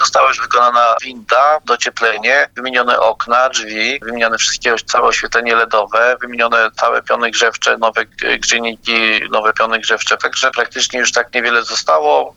Termomodernizacja Domu Kultury w Zwoleniu jest na zaawansowanym etapie. O aktualnym stanie prac mówi burmistrz Zwolenia Arkadiusz Sulima: